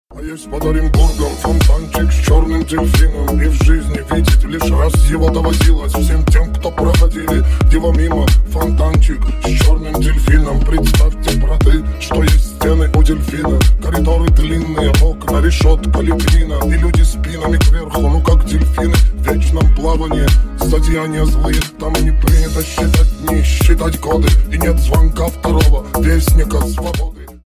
Клубные Рингтоны
Рингтоны Ремиксы » # Рэп Хип-Хоп Рингтоны